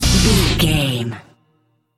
Dorian
Fast
drum machine
synthesiser
electric piano
Eurodance